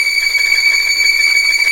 Index of /90_sSampleCDs/Roland L-CD702/VOL-1/STR_Vlns Tremelo/STR_Vls Tremolo